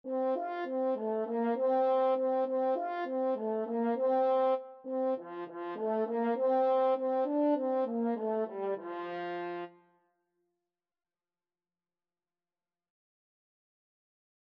World Trad. Pimpon (SpanishTraditional) French Horn version
Traditional Music of unknown author.
2/4 (View more 2/4 Music)
Quick
F major (Sounding Pitch) C major (French Horn in F) (View more F major Music for French Horn )
French Horn  (View more Beginners French Horn Music)
World (View more World French Horn Music)